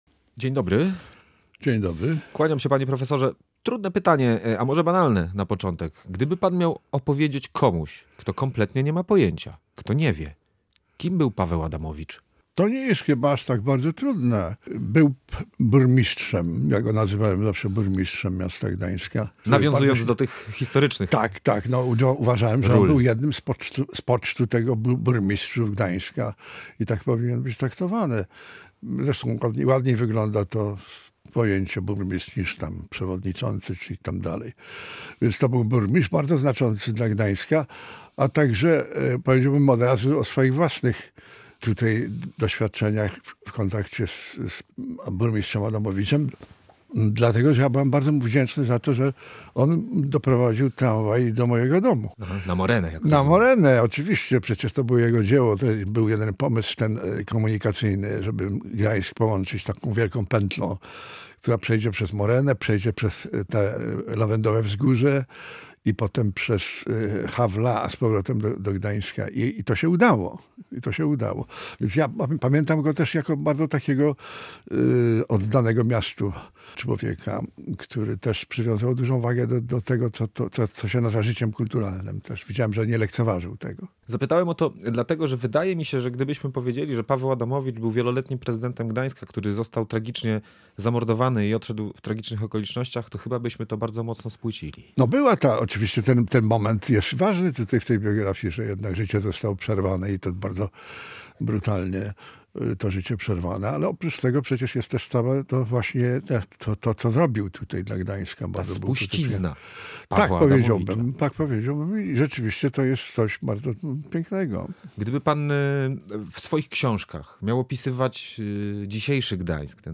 O dorobku Pawła Adamowicza i jego wkładzie w historię i rozwój Gdańska ze Stefanem Chwinem rozmawiał